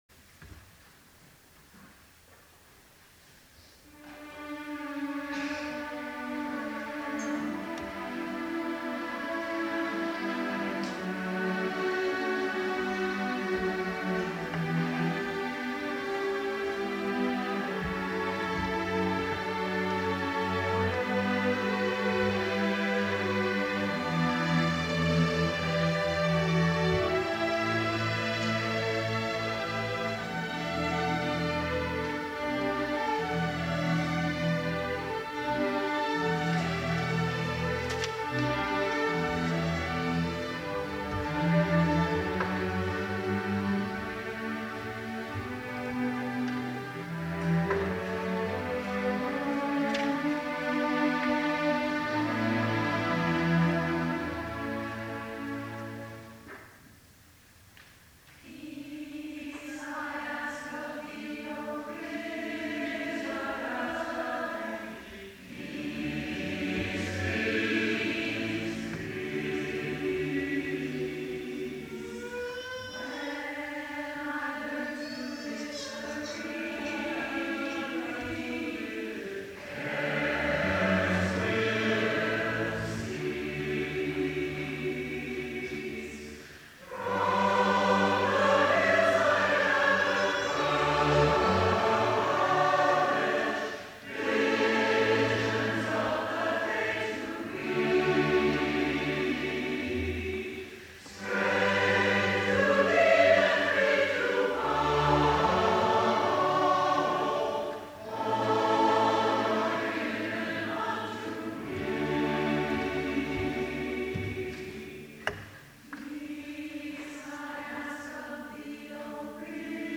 The string accompaniment is flowing.